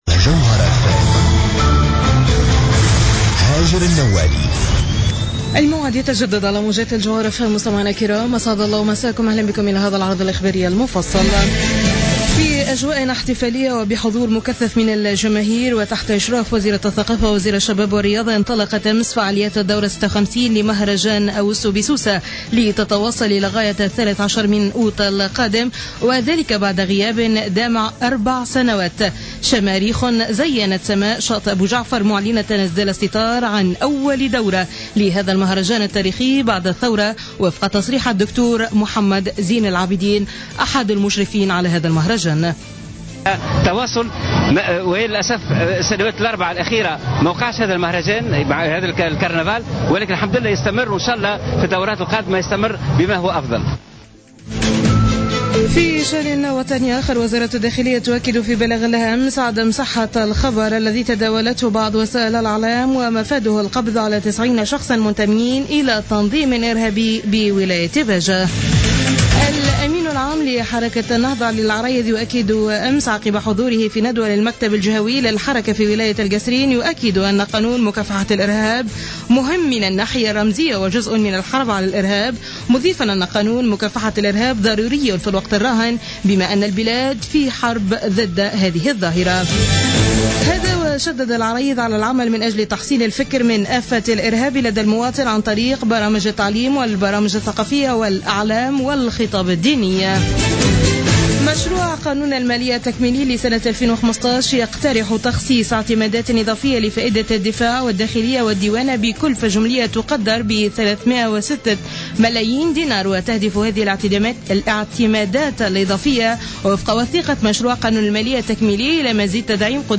نشرة أخبار منتصف الليل ليوم الإثنين 27 جويلية 2015